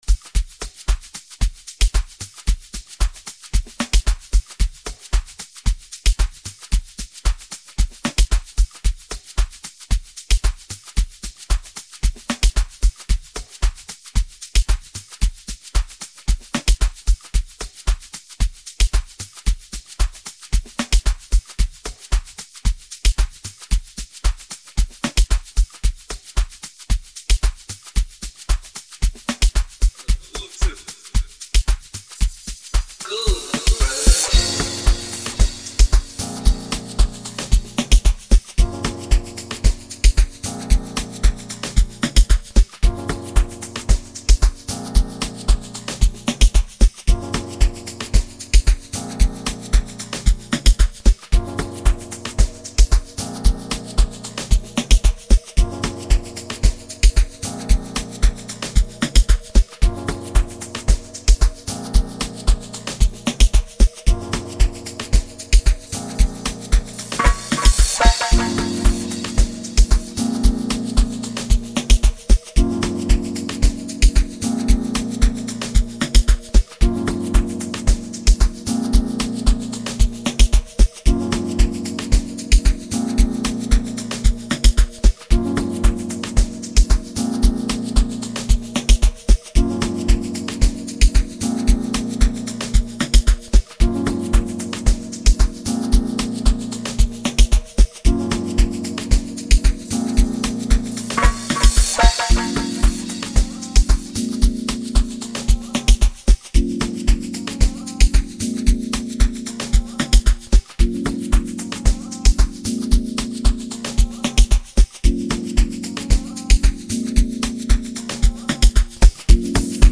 07:26 Genre : Amapiano Size